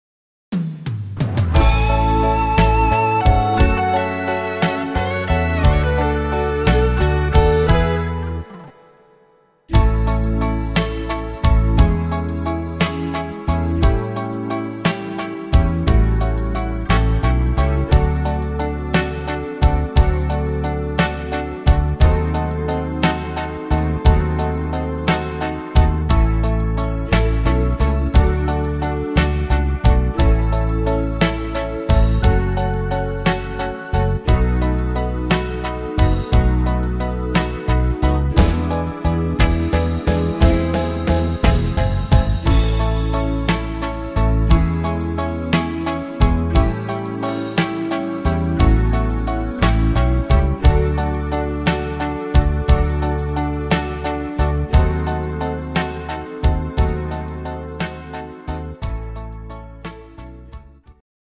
Rhythmus  Slowrock
Art  Weihnachtslieder